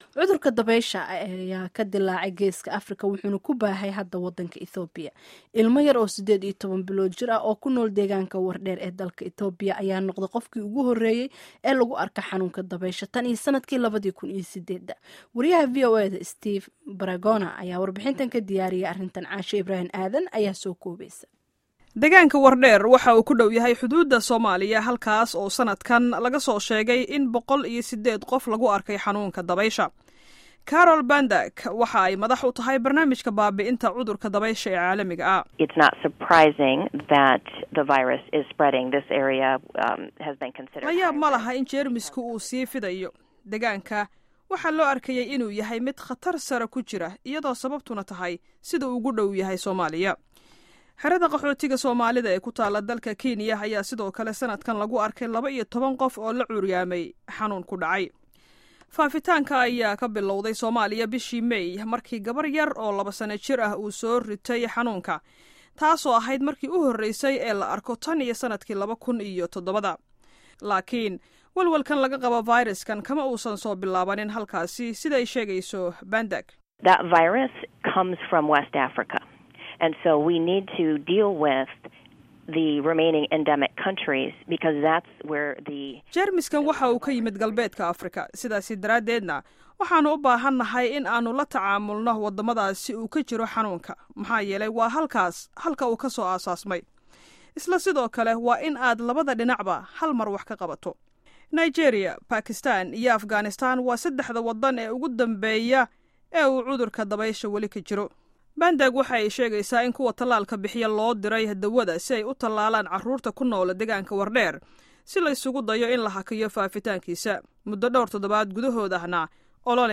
Dhageyso Warbixinta Cudurka Dabeysha